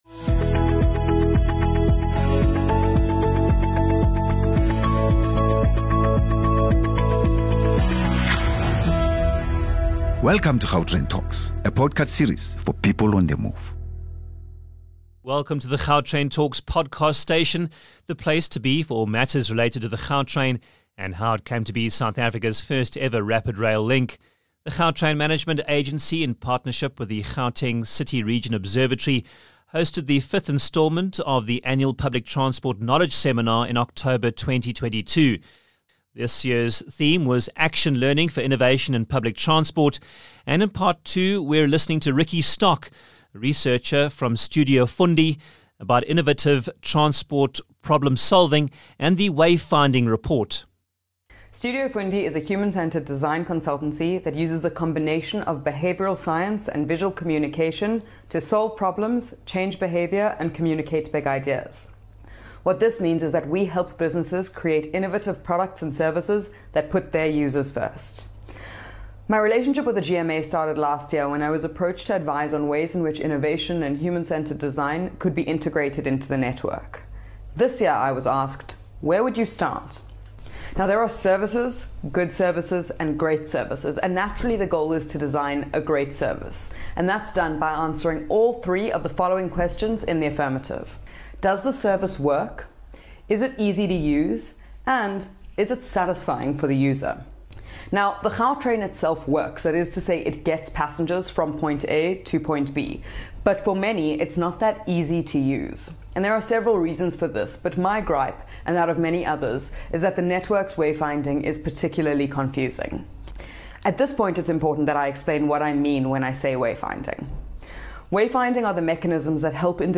The Gautrain Management Agency in partnership with the Gauteng City Region Observatory hosted the 5th installment of the annual Public Transport Knowledge Seminar in October 2022.